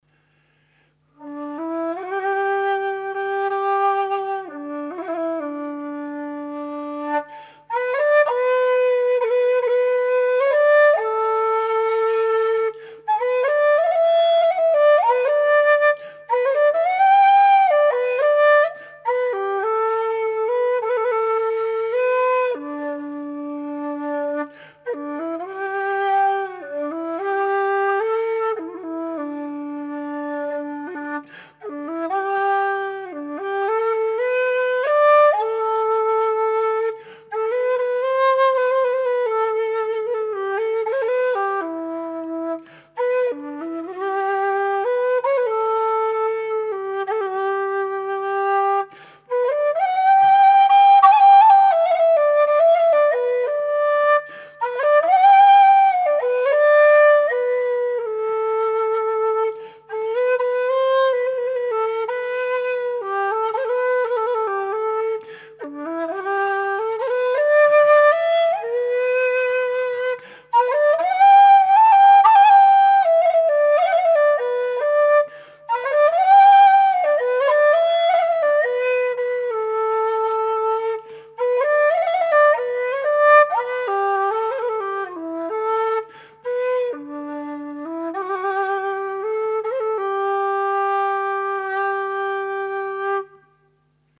Low D whistle
made out of thin-walled aluminium tubing with 23mm bore
Audio:LowD-impro1.mp3:) (Improvisation [with extra reverb])